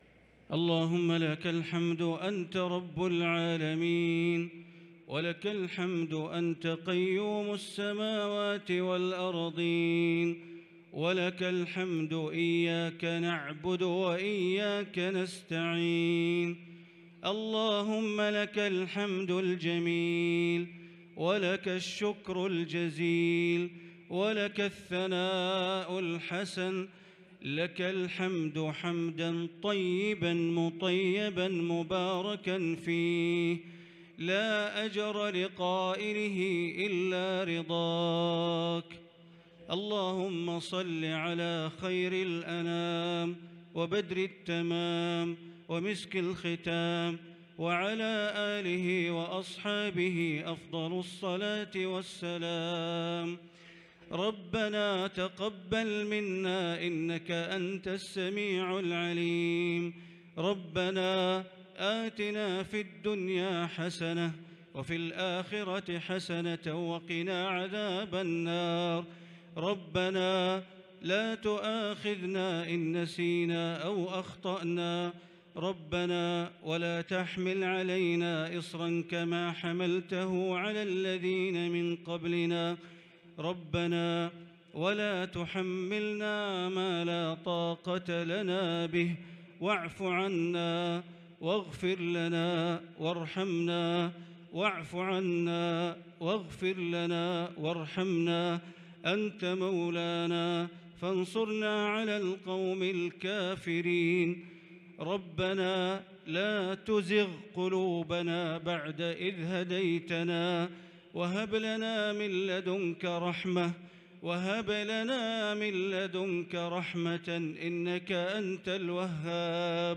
دعاء خاشع بكى وأبكي فيه الشيخ بندر بليلة المصلين | ليلة 6 رمضان 1443هـ > تراويح 1443 > التراويح - تلاوات بندر بليلة